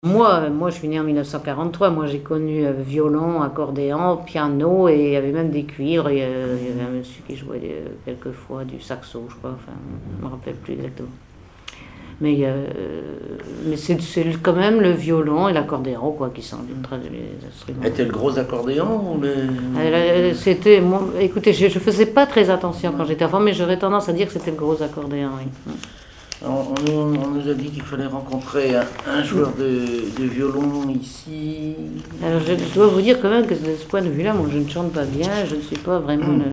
Conversation sur les musiciens de Saint-Pierre et Miquelon
Catégorie Témoignage